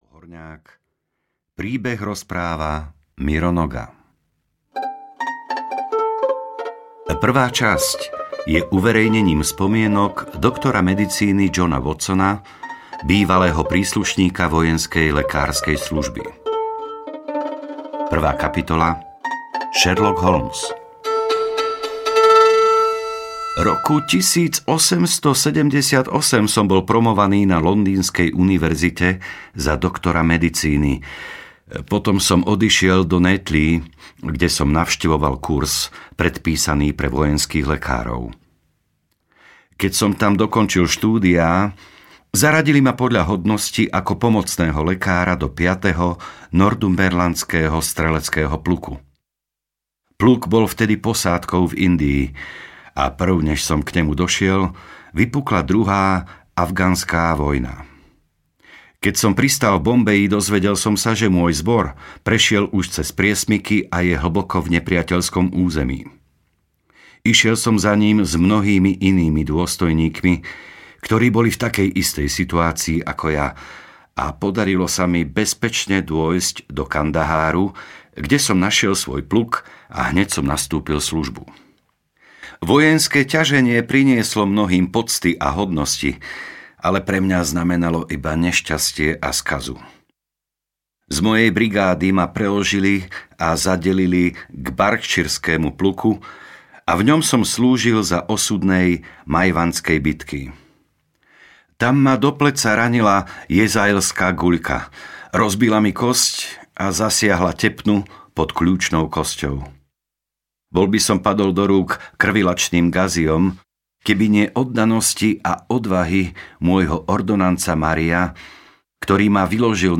Štúdia v červenom audiokniha
Ukázka z knihy